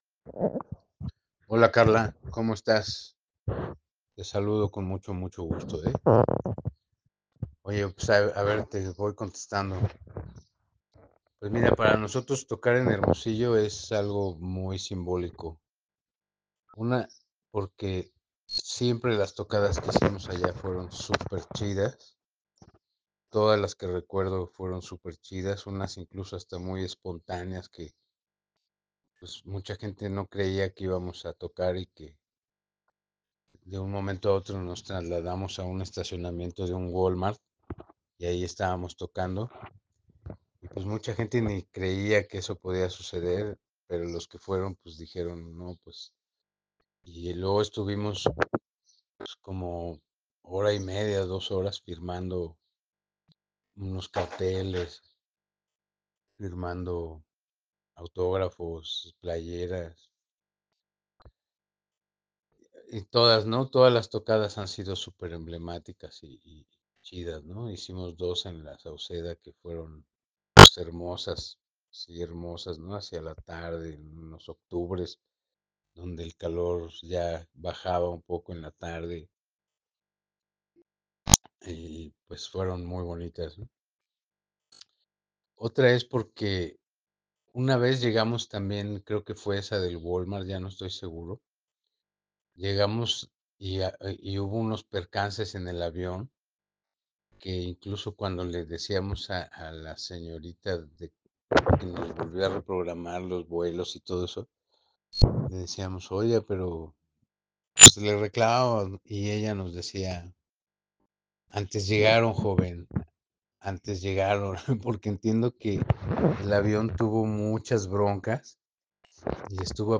La-Chicharra-Entrevista-La-Santa-Sabina-abr-2026.ogg